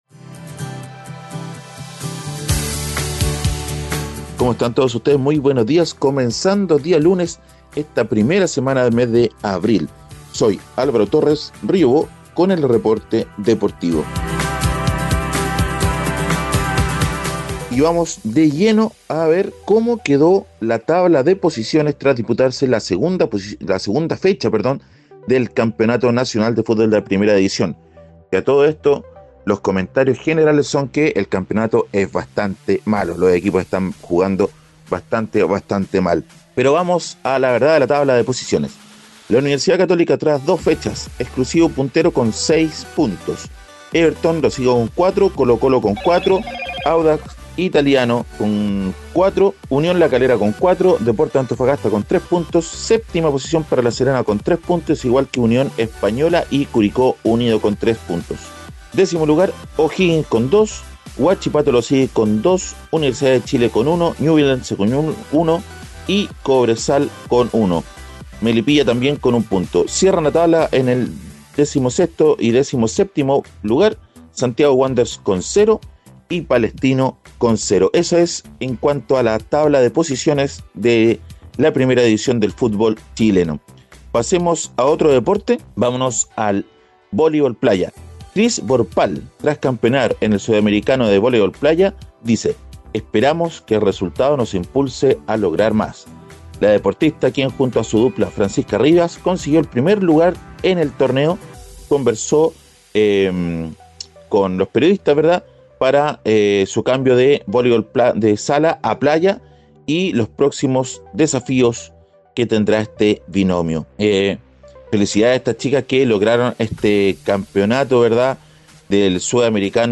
Reporte Deportivo - Lunes 05 de abril 2021